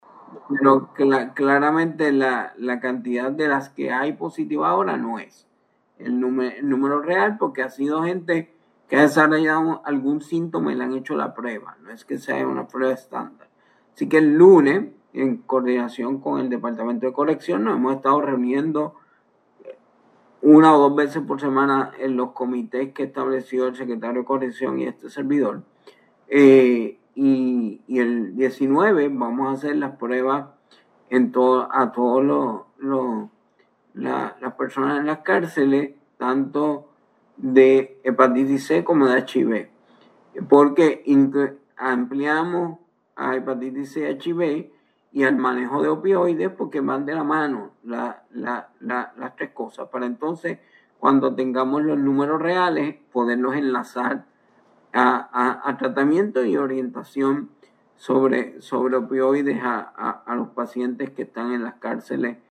El secretario de Salud, Víctor Ramos confirmó en Hora Exacta que el próximo lunes, 19 de mayo se realizarán las pruebas de Hepatitis C y Virus de la Inmunodeficiencia Humana (VIH) en las cárceles del Departamento de Corrección y Rehabilitación (DCR).